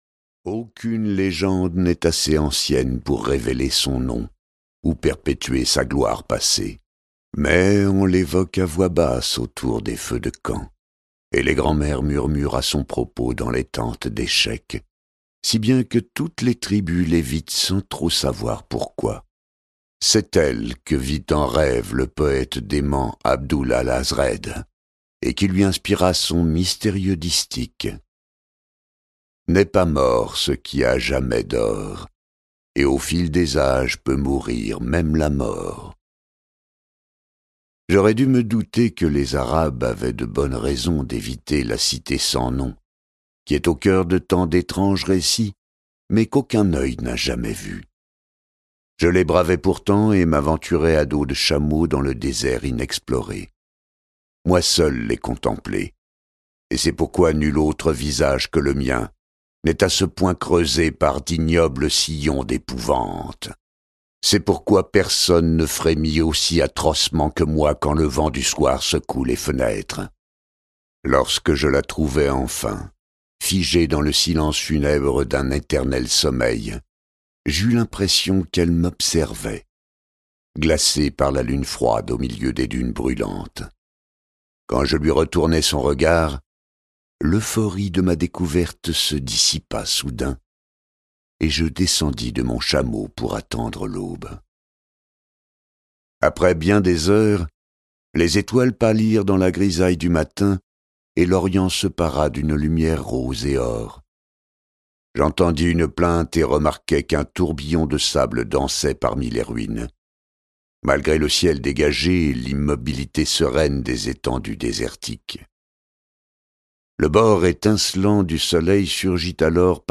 Le mythe de Cthulhu n'a jamais été aussi réel…Ce livre audio est interprété par une voix humaine, dans le respect des engagements d'Hardigan.